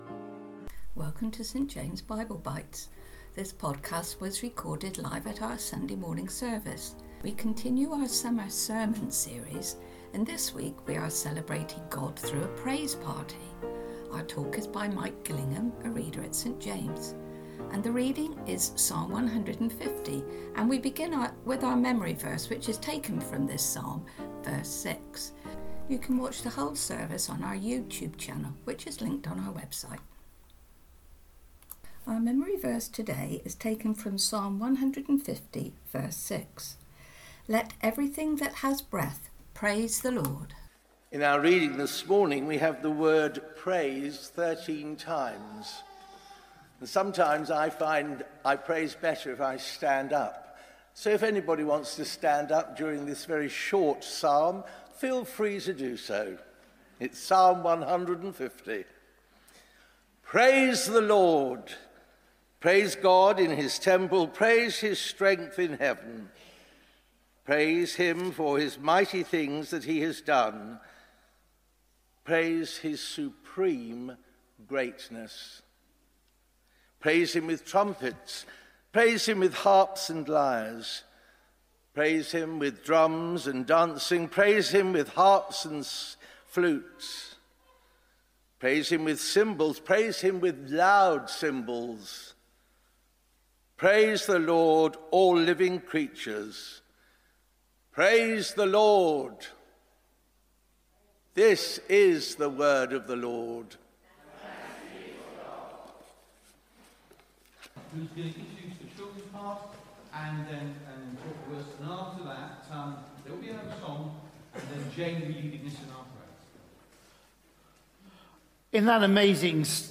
Sunday Talks